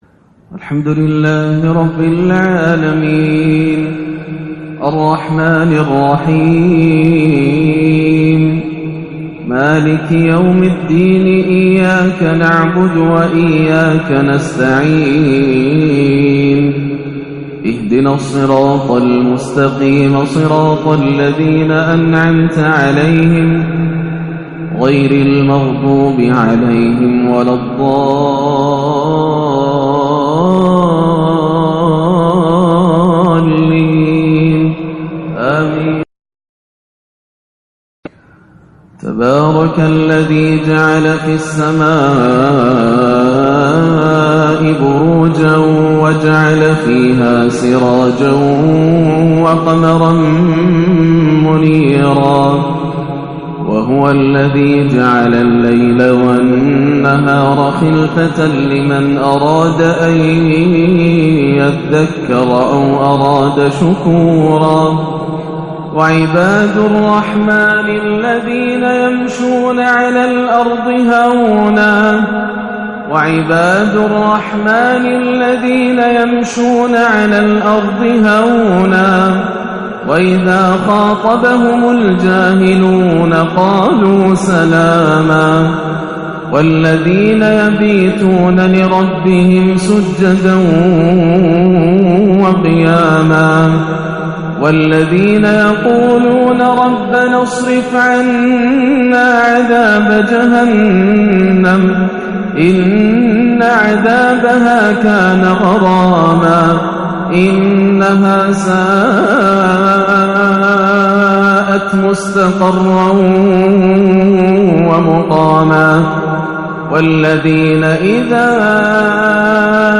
عشاء الثلاثاء 4-2-1439هـ أواخر سورتي الفرقان 61-77 و الأحزاب 70-73 > عام 1439 > الفروض - تلاوات ياسر الدوسري